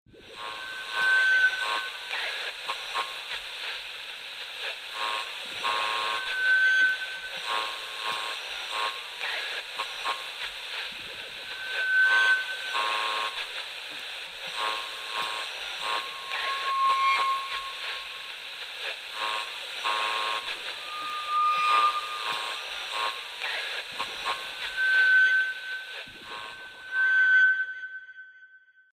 На этой странице собраны звуки, передающие атмосферу размышлений: задумчивые паузы, едва уловимые вздохи, фоновое бормотание.